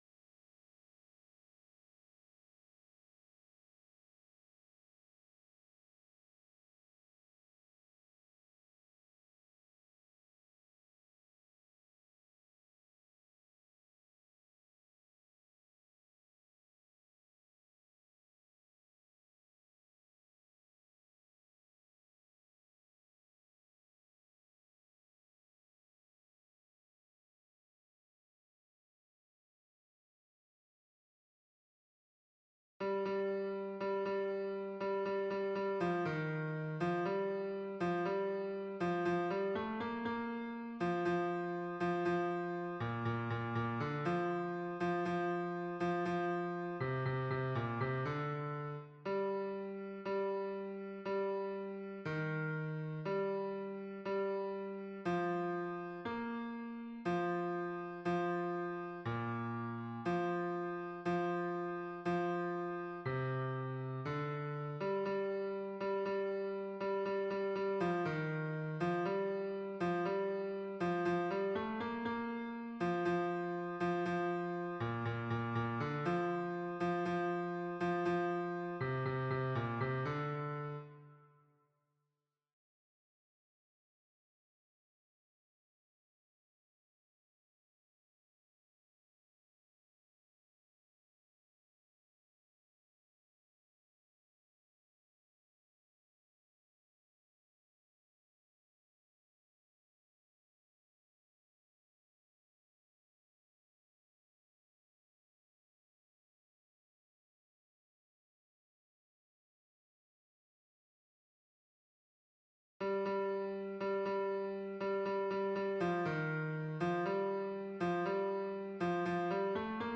MP3 version piano
Hommes